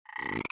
Wep Frog Inflate Sound Effect
Download a high-quality wep frog inflate sound effect.
wep-frog-inflate.mp3